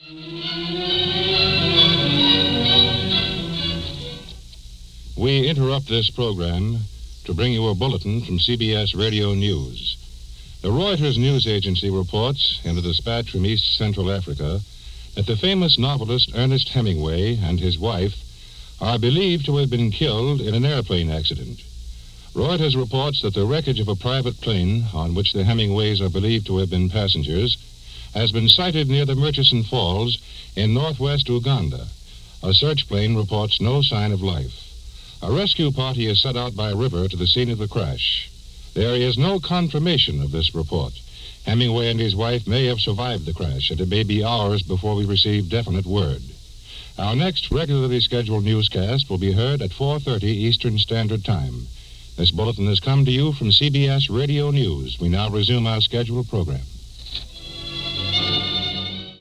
But for several hours it was assumed the iconic American author had died in the crash – and this bulletin which came in the middle of concert by the Longines Symphonette arrived with sobering news that, even though unconfirmed, it was almost certain we had lost Ernest Hemingway.
Here’s the bulletin as it was heard on January 24, 1954.